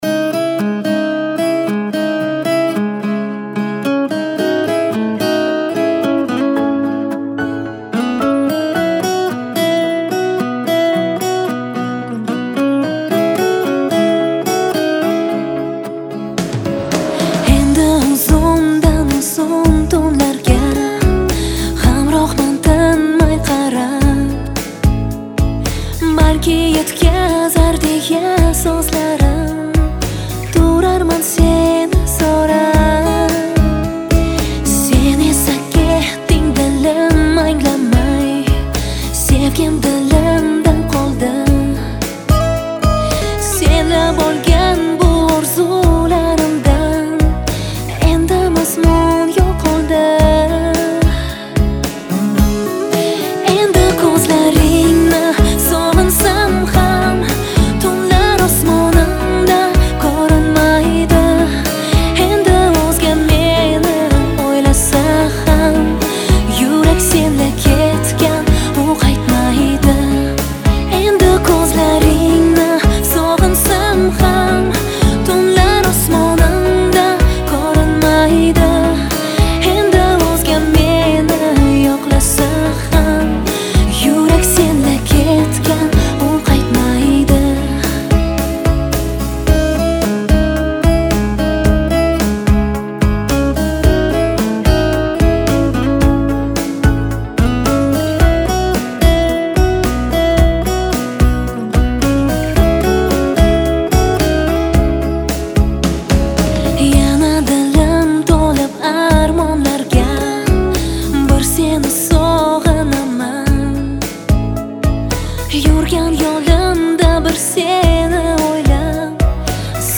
• Жанр: New Uzb / Узбекские песни